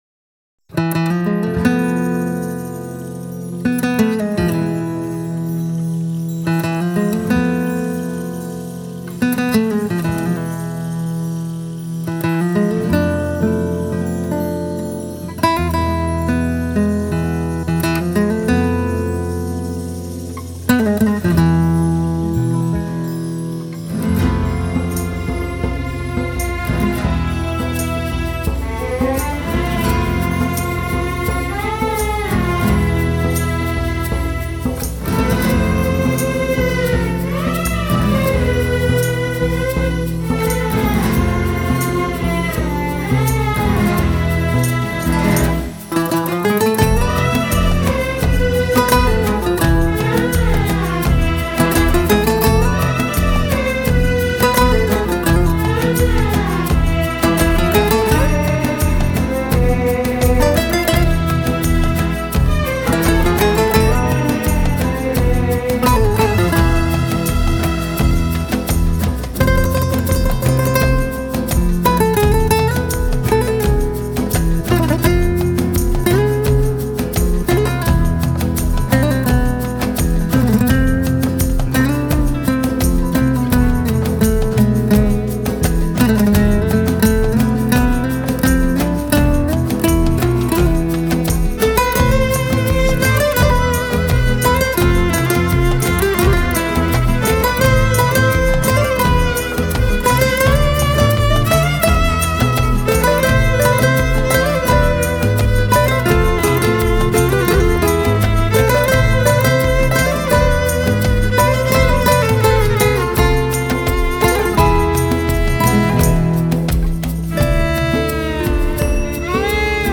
آرامش بخش
عصر جدید , گیتار